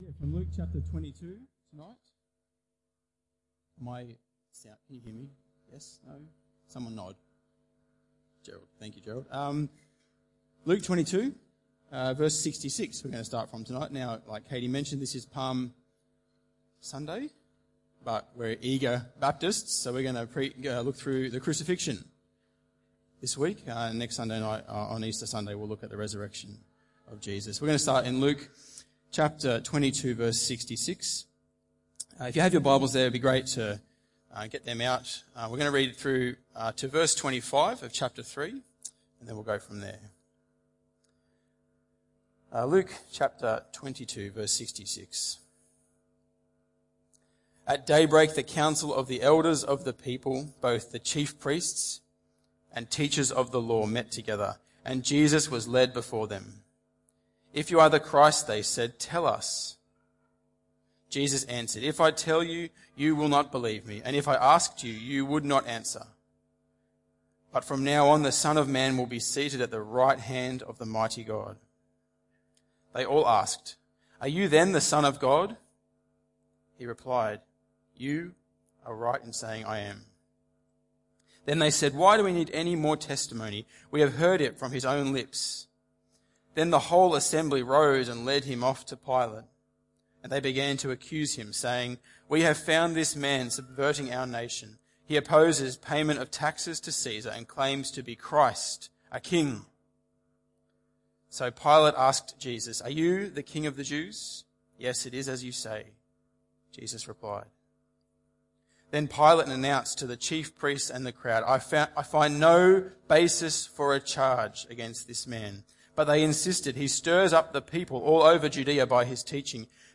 Tagged with Sunday Evening